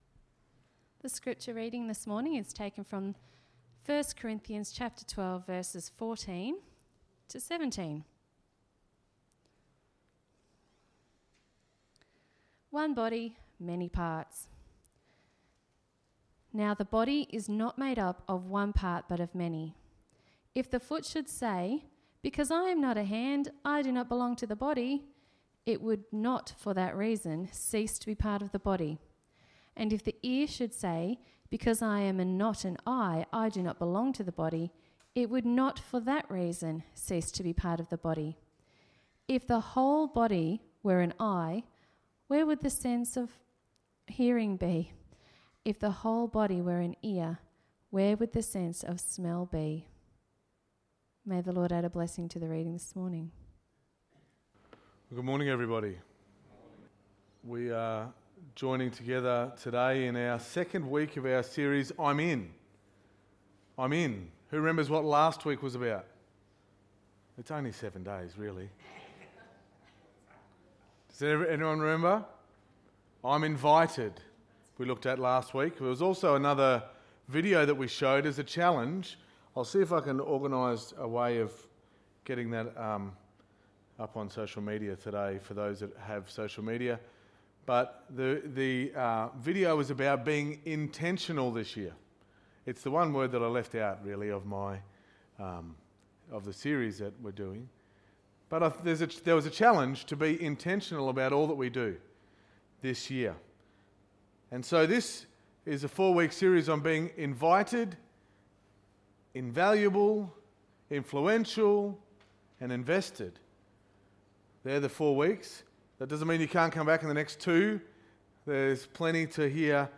Sermon 12.01.2020